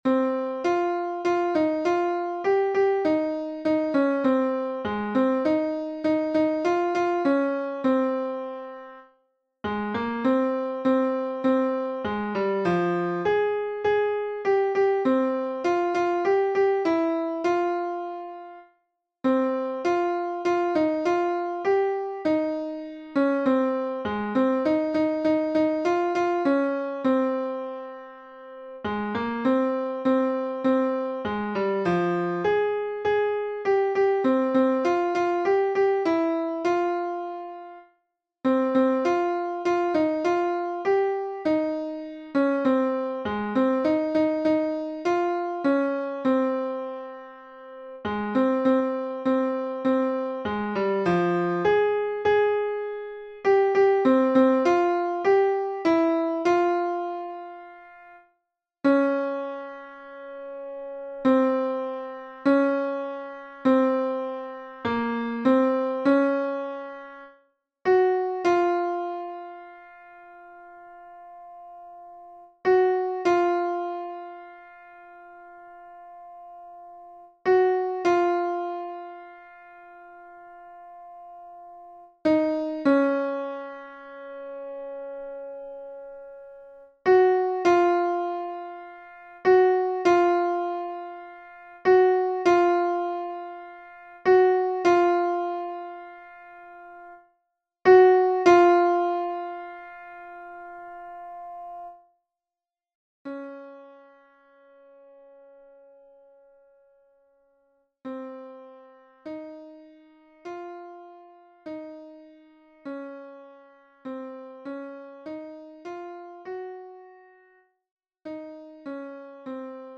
Alto (version piano)